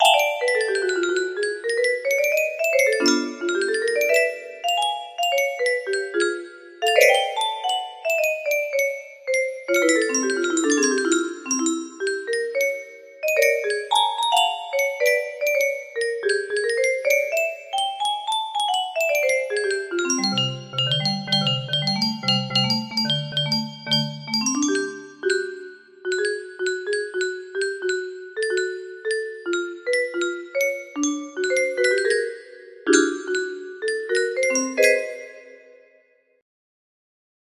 Gamin bois music box melody